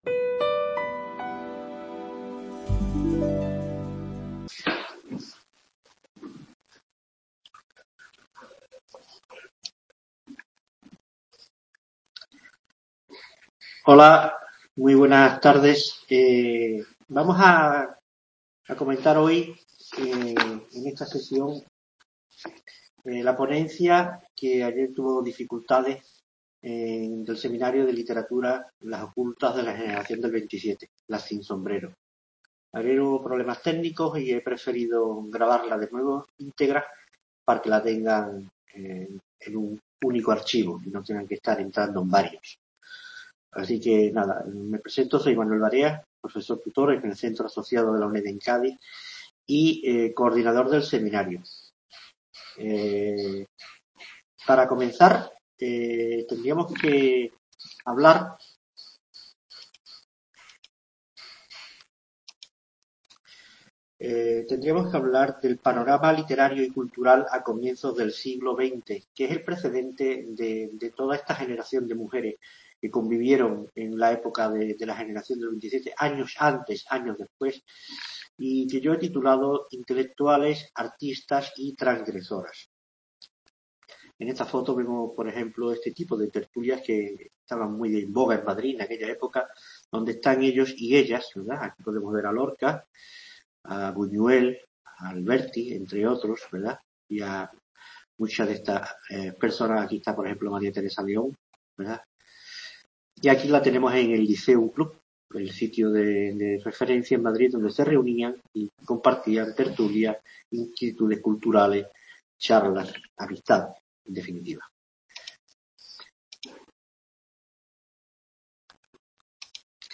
PONENCIA